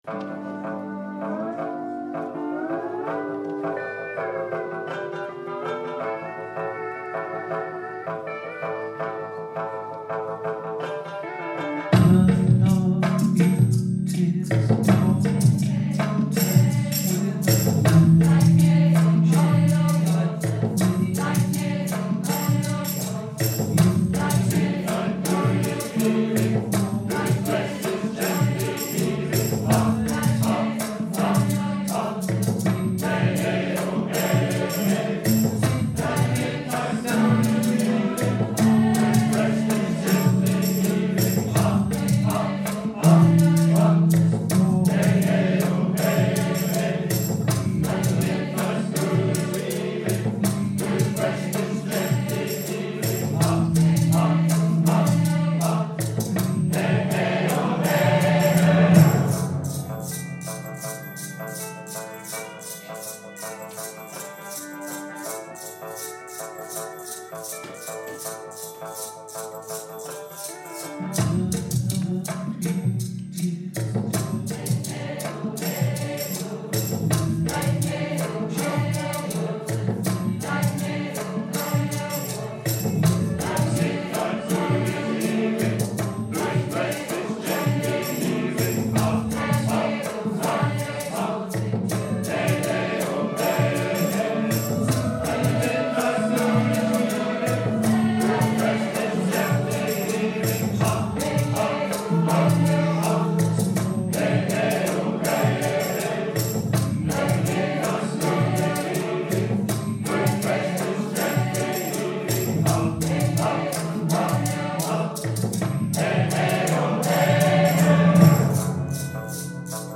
Here’s my first attempt to sketch out the basic melody.